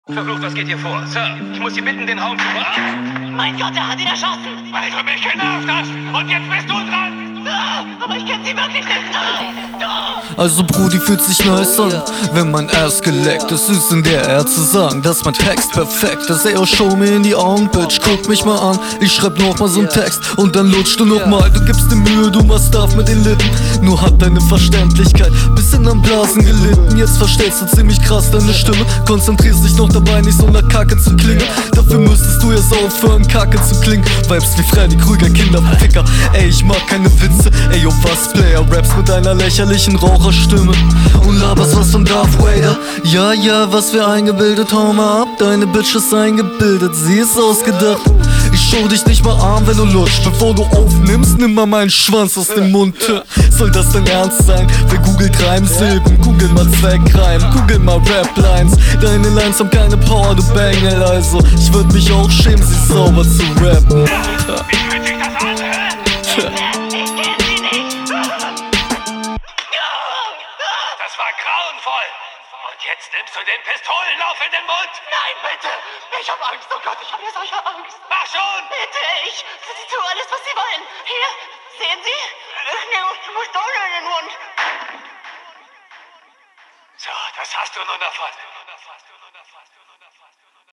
Flow ziemlich reingeschissen auf dem Beat.
Filmausschnitte kommen aber immer geil.
das klingt ziemlich unroutiniert, nicht dein beat. eingebildet-line drückt text in den minusbereich, sonst auch …